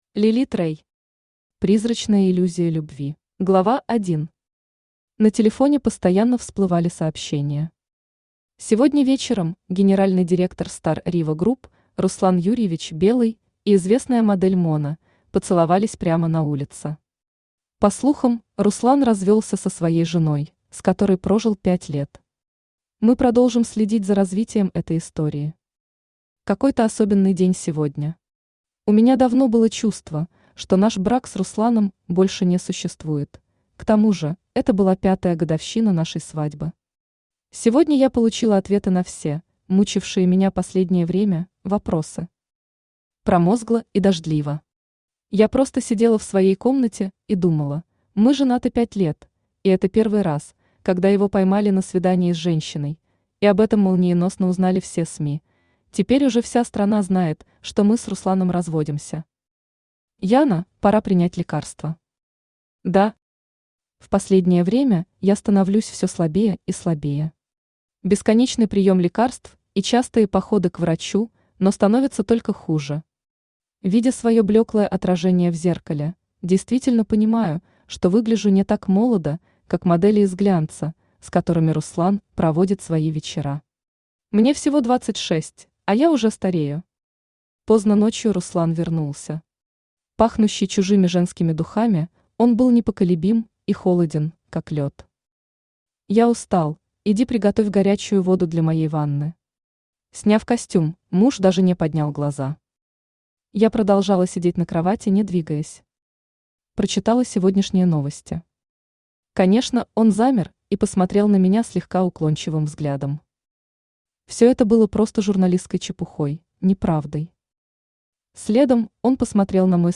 Аудиокнига Призрачная иллюзия любви | Библиотека аудиокниг
Aудиокнига Призрачная иллюзия любви Автор Лилит Рэй Читает аудиокнигу Авточтец ЛитРес.